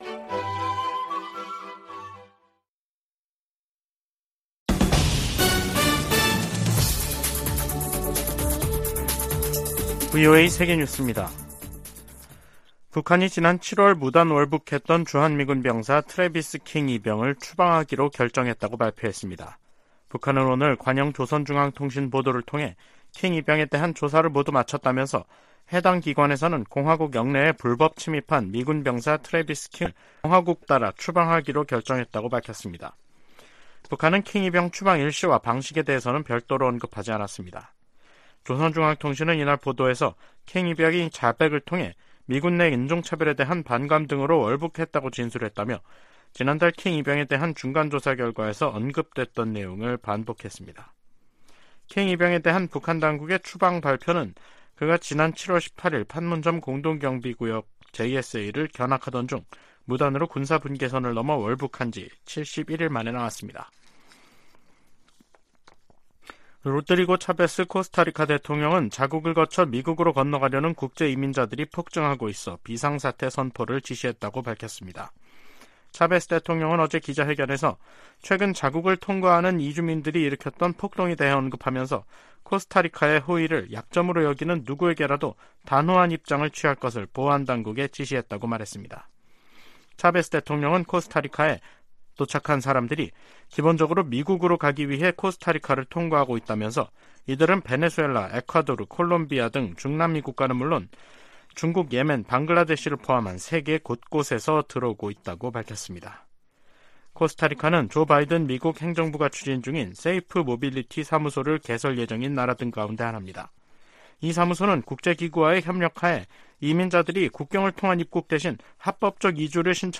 VOA 한국어 간판 뉴스 프로그램 '뉴스 투데이', 2023년 9월 27일 2부 방송입니다. 미 국무부는 미한 연합훈련을 '침략적 성격이 강한 위협'이라고 규정한 북한 유엔대사의 발언에 이 훈련은 관례적이고 방어적인 것이라고 반박했습니다. 한국의 신원식 국방부 장관 후보자는 9.19 남북 군사합의 효력을 빨리 정지하도록 추진하겠다고 밝혔습니다. 미국과 한국, 일본 등은 제 54차 유엔 인권이사회에서 북한의 심각한 인권 유린 실태를 강력하게 규탄했습니다.